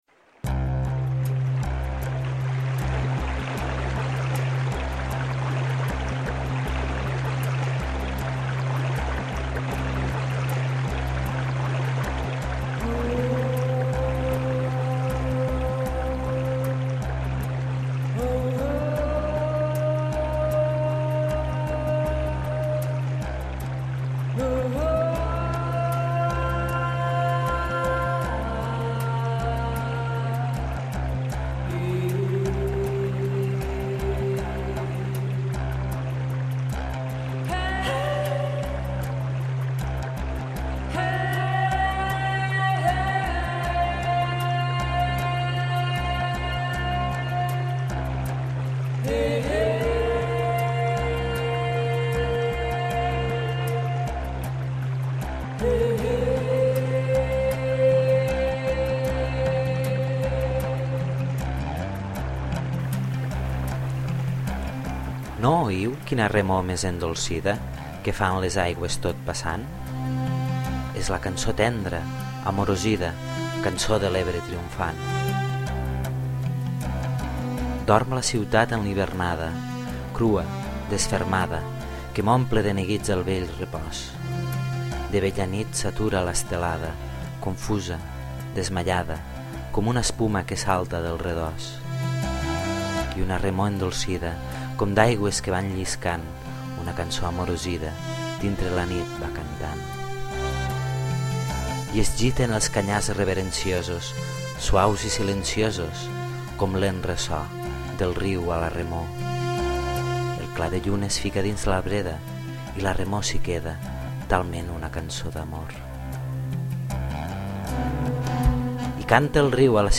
La música és de Jami Sieber.